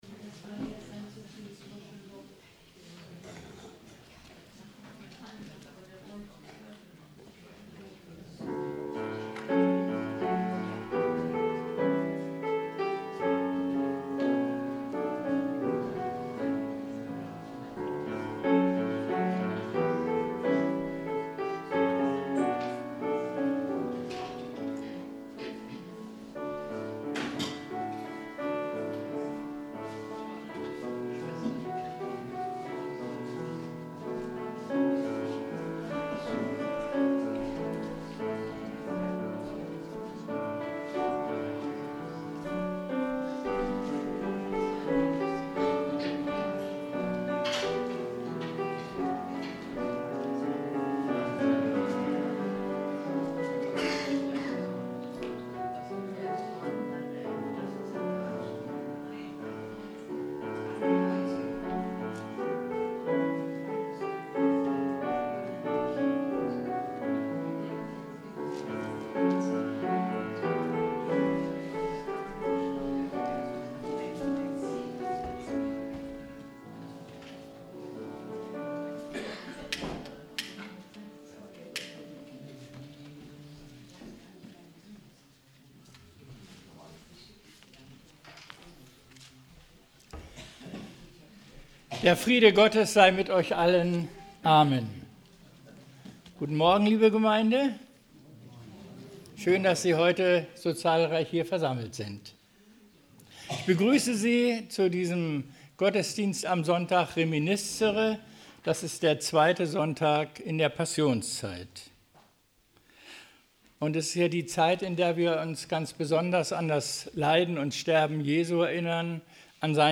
Gottesdienst - 01.03.2026 ~ Peter und Paul Gottesdienst-Podcast Podcast